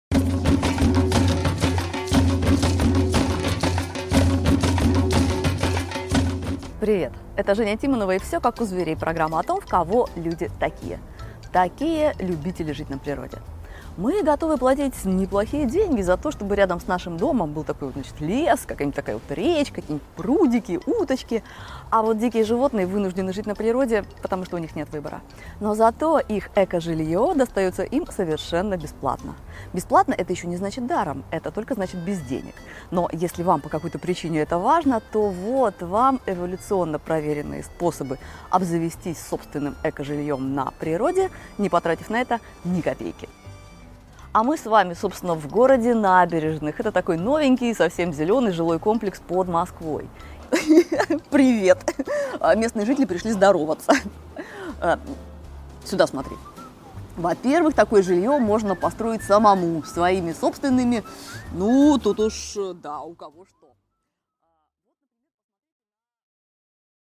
Аудиокнига Решаем квартирный вопрос: 10 проверенных способов | Библиотека аудиокниг
Прослушать и бесплатно скачать фрагмент аудиокниги